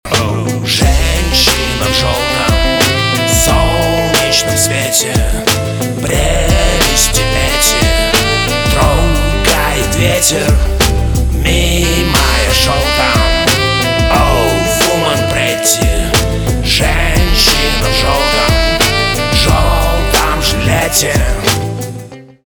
русский рок
саксофон